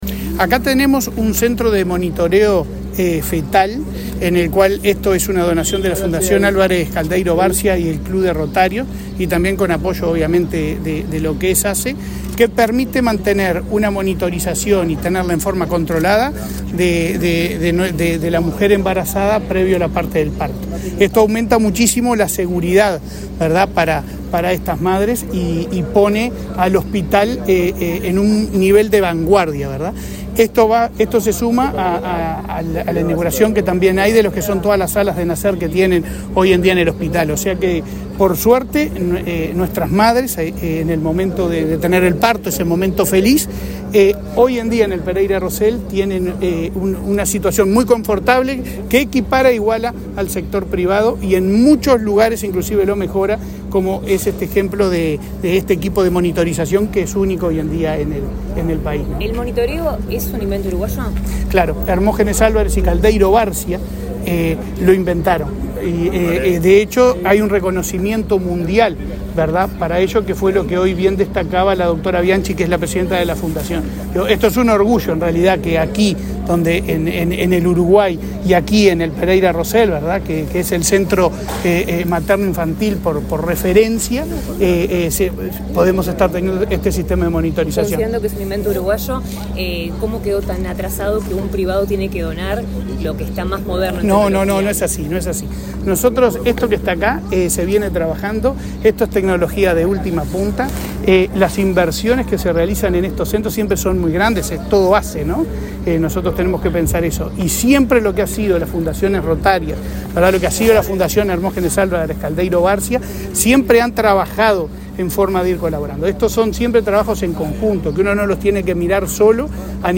Declaraciones del presidente de ASSE, Leonardo Cipriani
Declaraciones del presidente de ASSE, Leonardo Cipriani 08/12/2023 Compartir Facebook X Copiar enlace WhatsApp LinkedIn Este viernes 8, el presidente de la Administración de los Servicios de Salud del Estado (ASSE), Leonardo Cipriani, dialogó con la prensa en el hospital Pereira Rossell, luego de participar en la inauguración de un sistema de monitoreo computarizado e inalámbrico para embarazadas.